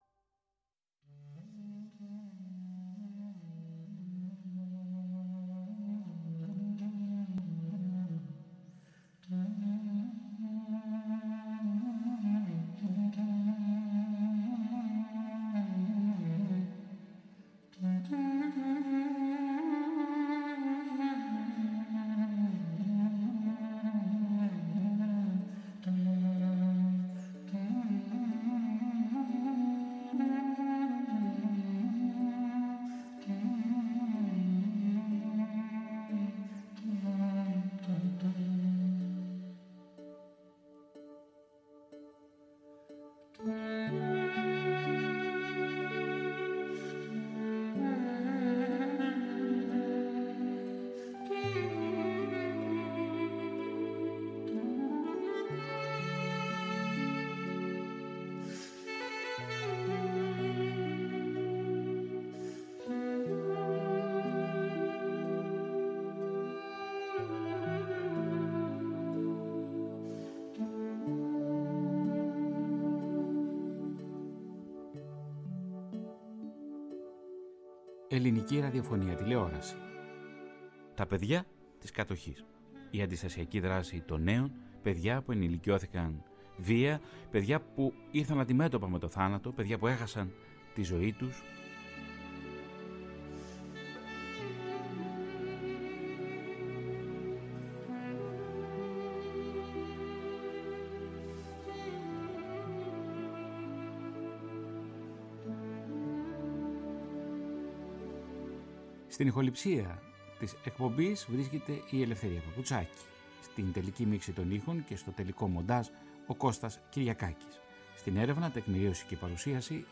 Η “Αφύλαχτη Διάβαση” αφιερώνει όλο τον Οκτώβριο στα χρόνια της φασιστικής και ναζιστικής Κατοχής και στην Απελευθέρωση της πόλης των Αθηνών με τέσσερα συγκλονιστικά ραδιοφωνικά ντοκιμαντέρ που θα μεταδοθούν από τη Φωνή της Ελλάδας στις 4, 11, 18 και 25 Οκτωβρίου από 13:00 έως 14:00.
Ντοκιμαντέρ